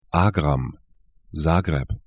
Aussprache
Agram 'a:gram Zagreb